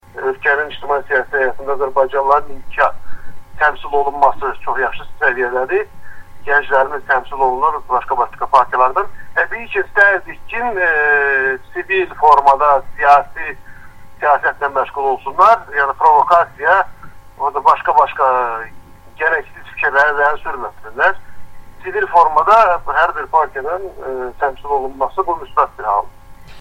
O, AzadlıqRadiosu-na müsahibəsində deyir ki, əgər müxalifətin iddia etdiyi kimi, qanunsuzluqlar, saxtakarlıqlar olsaydı, o zaman bir çox dairədə ikinci tur elan edilməzdi: “Təkcə azərbaycanlılar yaşayan yerlərdə ikinci tur elan etməyiblər, başqa dairələrdə də əsas rəqiblər baryeri aşa bilməyiblər”.